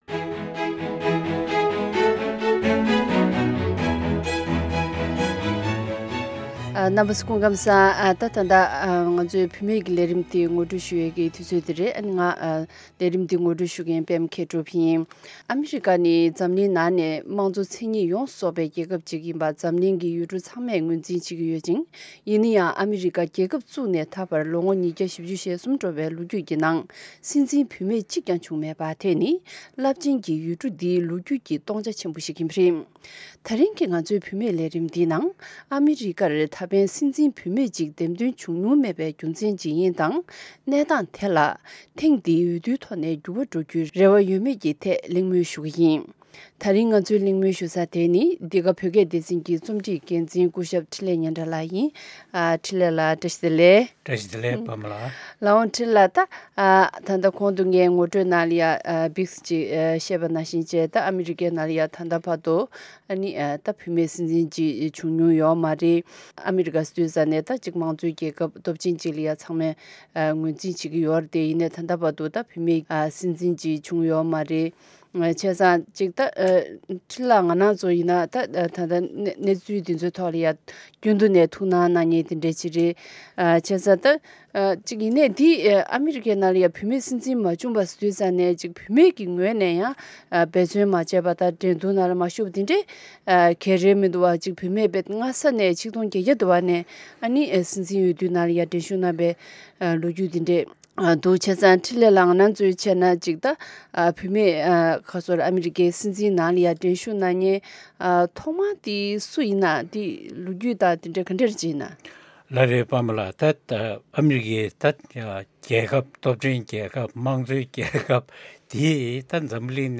གླེང་མོལ་ཞུ་ཡི་རེད།།